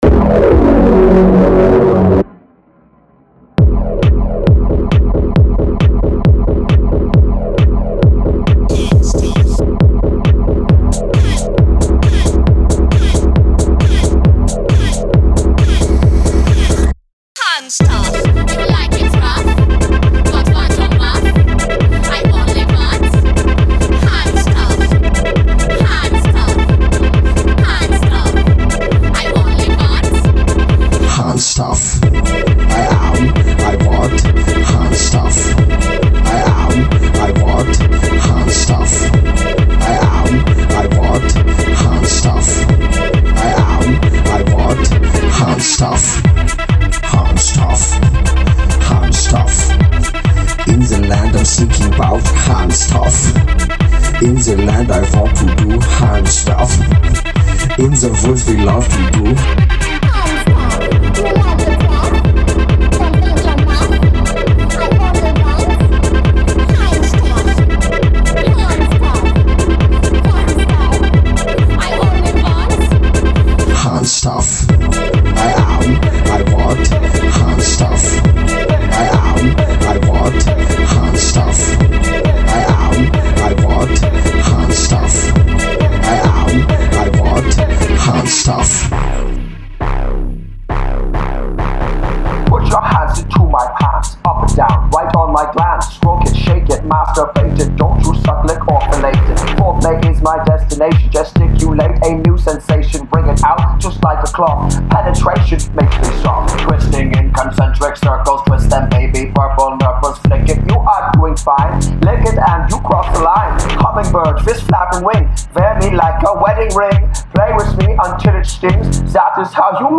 in the german techno tradition.